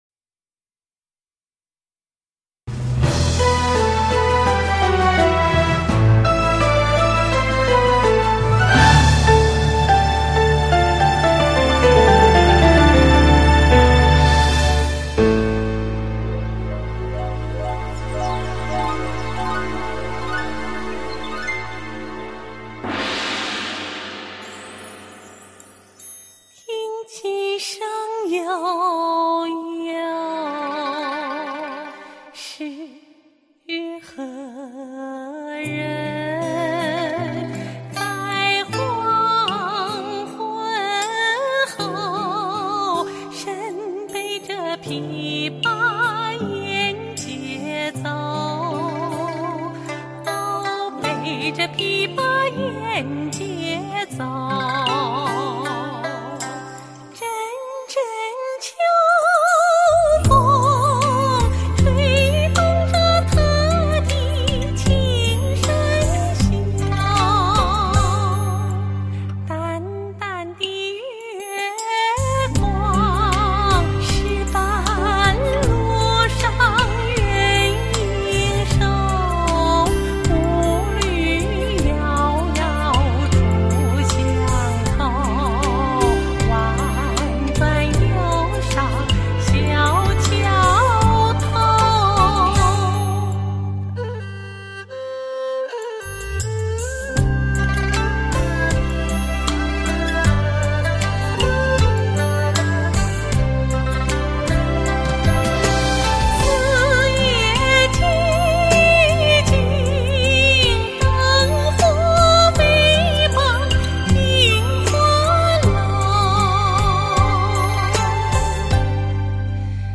类型: 天籁人声
兰芳之后中国第一当家男旦、被誉为“中国国宝级艺术家”。